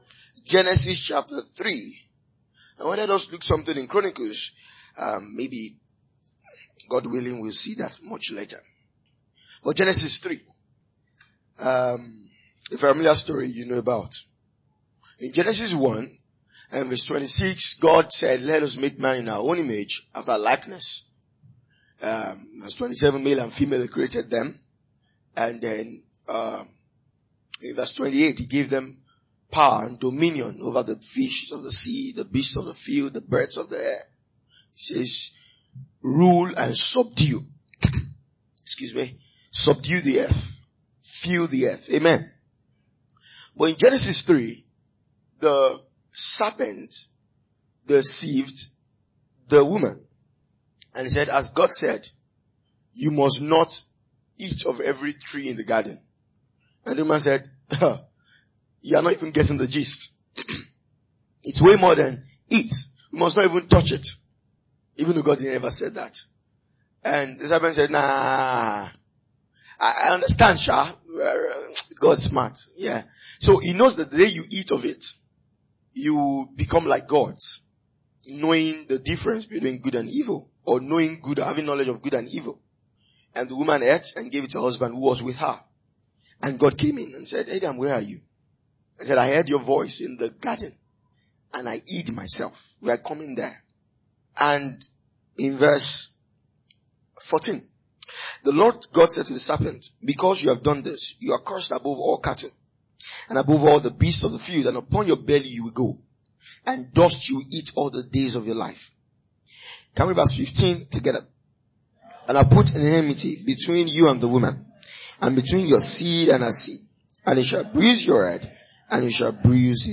A teaching from our annual workers’ meeting. it urges believers to stay active and vigilant in the spiritual battle. The work of ministry is a fight against the forces of evil, requiring every Christian to stand guard and remain watchful, even within the local church.